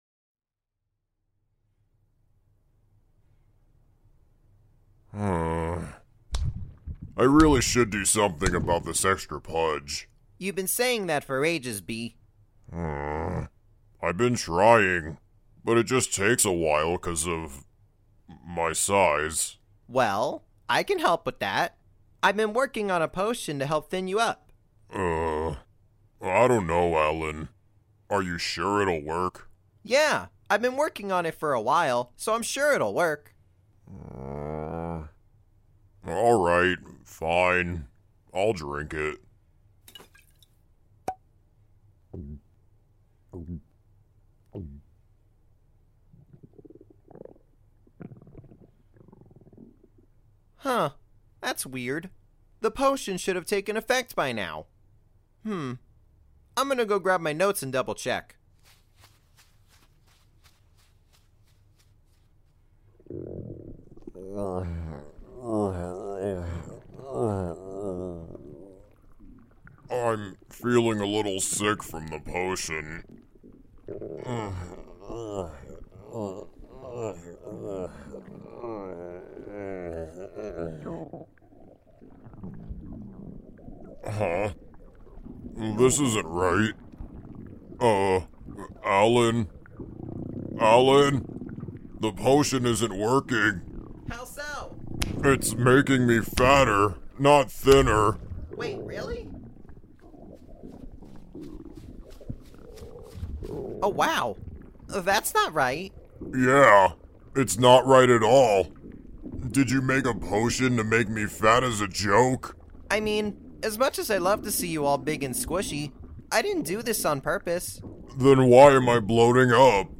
*As always, this audio is better experienced with headphones* If you would like to help me and my work